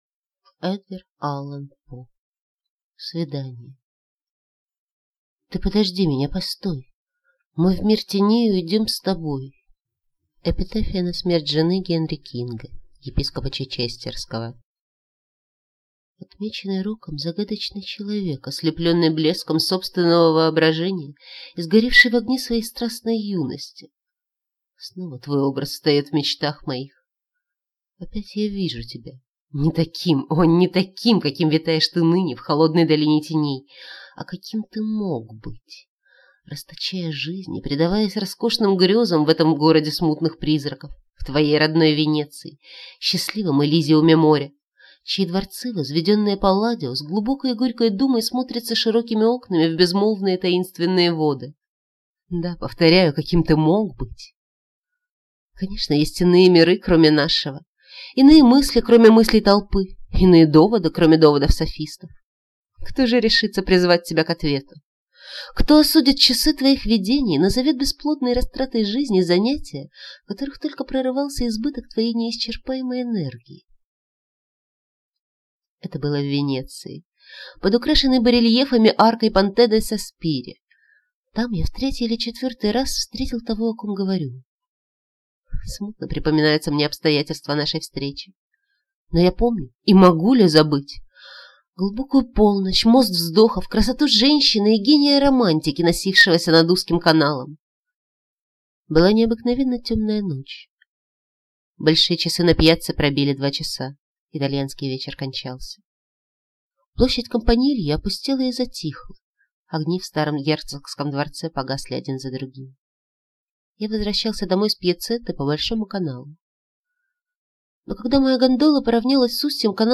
Аудиокнига Свидание | Библиотека аудиокниг